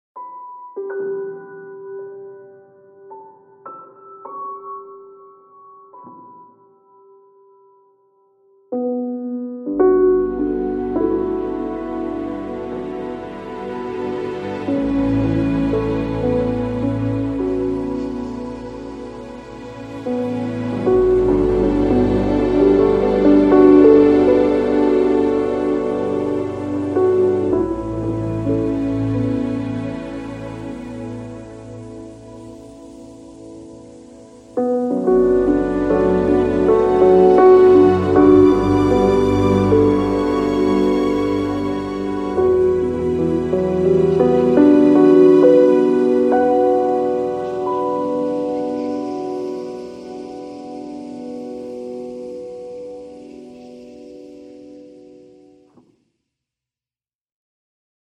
复古爵士钢琴 Soundiron Delphi Piano 01 Autumn 1958 KONTAKT-音频fun
Soundiron Delphi Piano 01 Autumn 1958 是一款基于 Kontakt 的虚拟钢琴音源，它采样了一架具有 50 年代末期爵士、蓝调和大乐队风格的老式立式钢琴，拥有丰富、浑浊和模糊的声音和氛围。这款音源不仅提供了原始的钢琴音色，还包含了由钢琴声音加工而成的多种环境垫音、演变无人机、冲击音效等，适合用于创造性的音乐制作和声音设计。
- 多采样的钢琴音符，包括 9 个力度层、6 个轮换、释放音、簇音、滑音、无音键和踏板噪音